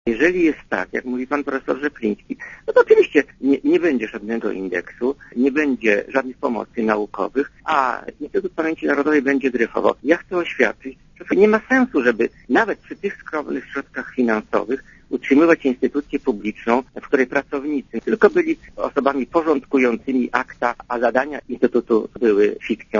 Posłuchaj Leona Kieresa, szefa IPN
Zapytany w Radiu Zet, czy poda się do dymisji (o co apelował w niedzielę przedstawiciel SLD w sejmowej Komisji ds. Służb Specjalnych Jacek Zdrojewski), Kieres odparł, że nie chciał się tym chwalić, ale już 2 lutego kolegium IPN - w którego skład wchodzi także reprezentant SLD - poprosiło go jednogłośnie, by "tego rodzaju wniosków nie składał".